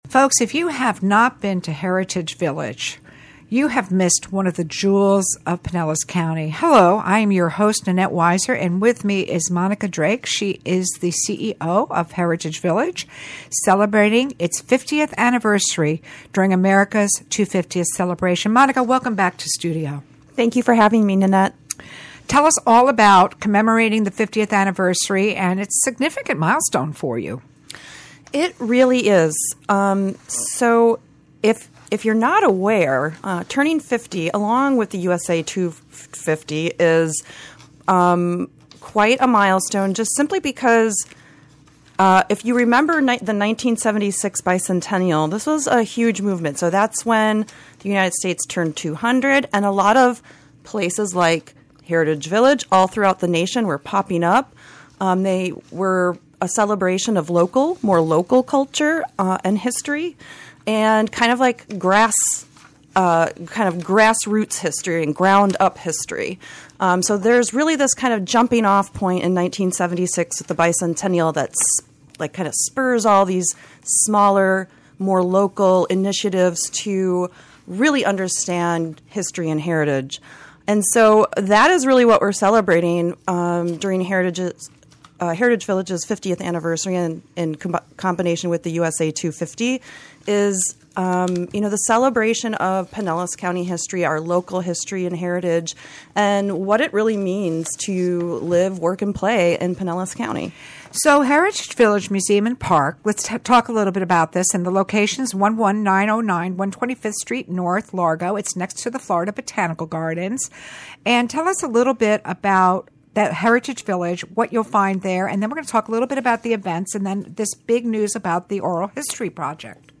Lunchtime Conversation